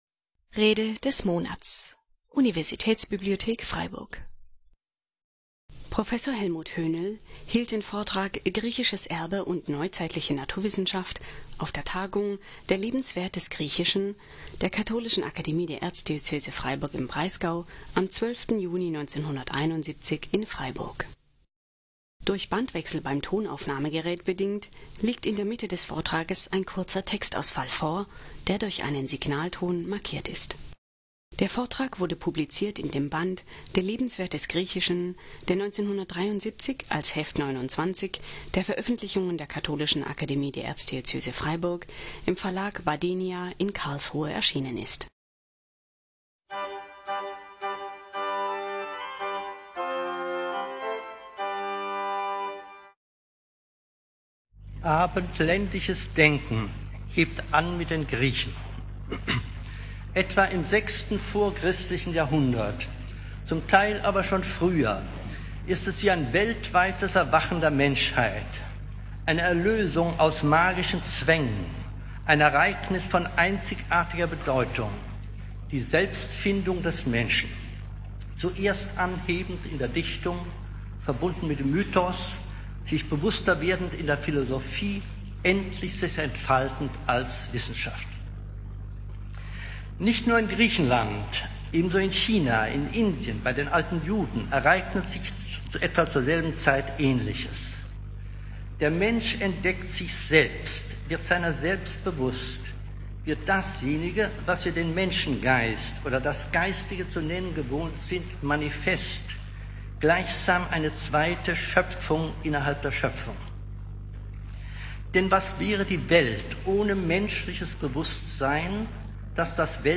Griechisches Erbe und neuzeitliche Naturwissenschaft (1971) - Rede des Monats - Religion und Theologie - Religion und Theologie - Kategorien - Videoportal Universität Freiburg
Durch Bandwechsel beim Tonaufnahmegerät bedingt, liegt in der Mitte des Vortrag eine kurzer Textausfall vor, der durch einen Pausenton markiert ist.